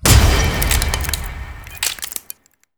failsound-screenbreak_unused.wav